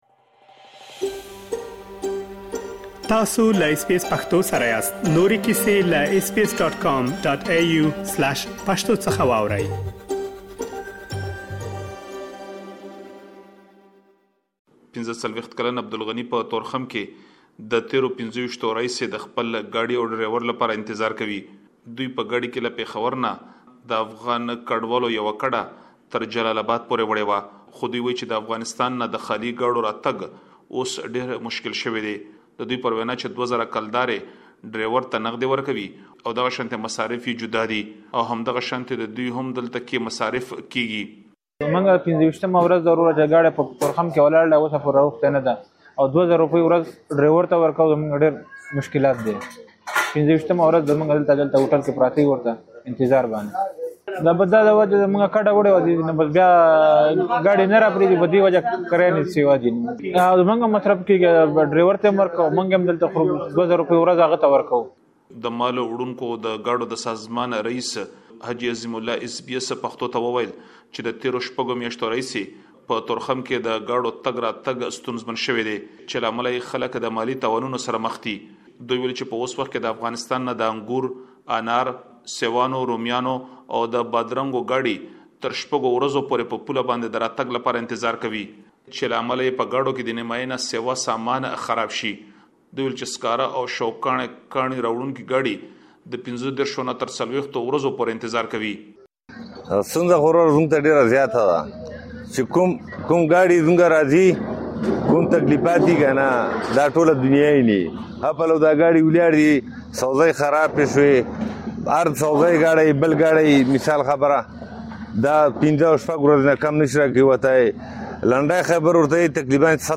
مهرباني وکړئ لا ډېر معلومات په رپوټ کې واورئ.